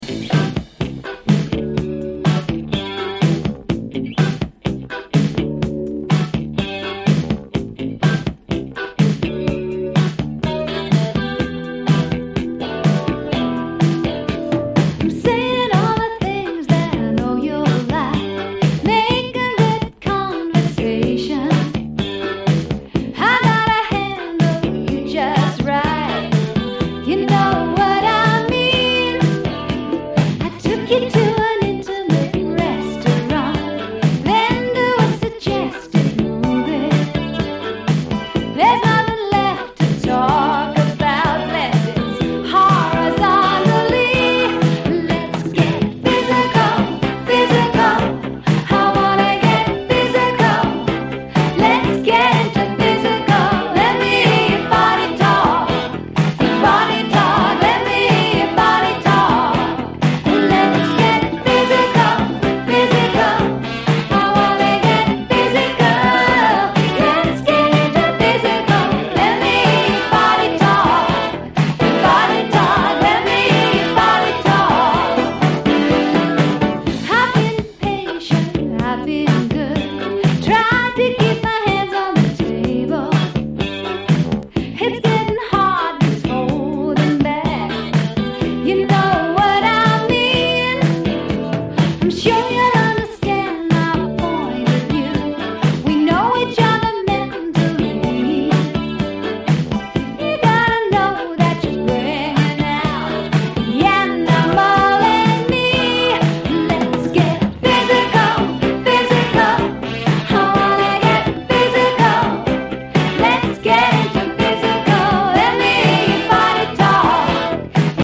ロック色を少々取り入れたアップテンポでディスコ調の人気曲！